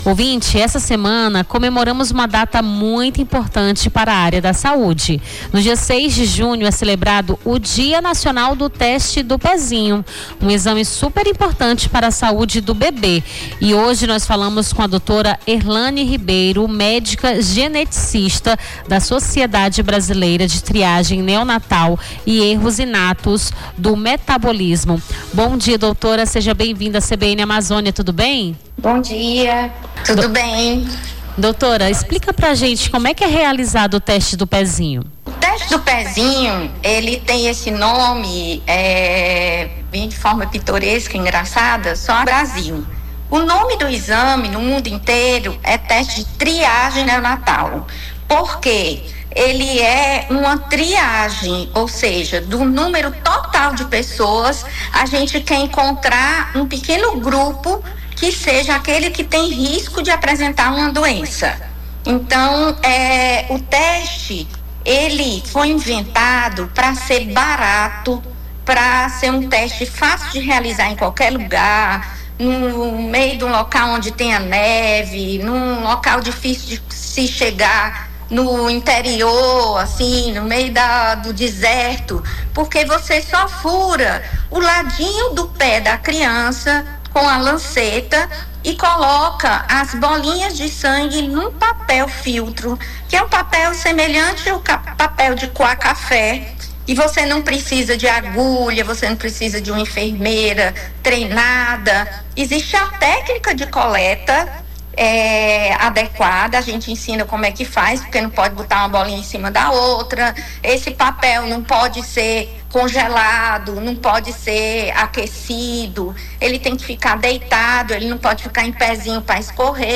Nome do Artista - CENSURA - ENTREVISTA DIA NACIONAL DO TESTE DO PEZINHO (04-06-25).mp3